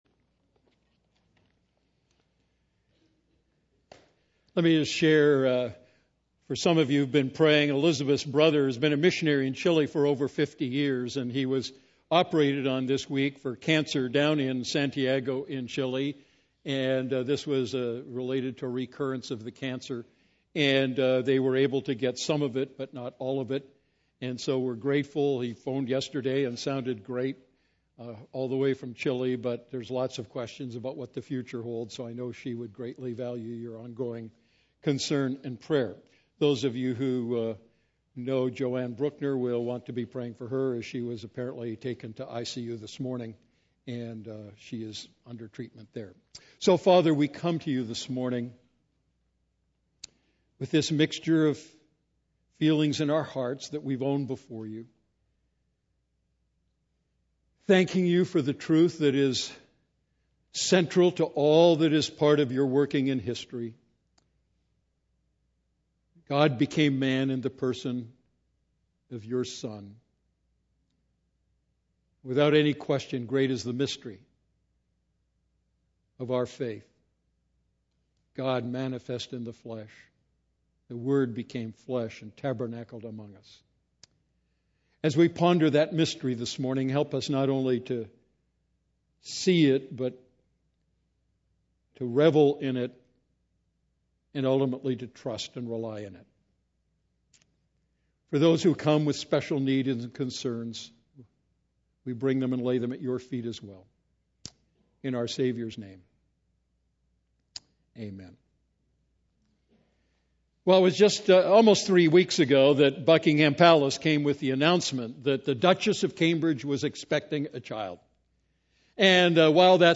A message from the series "The Wonder of Christmas."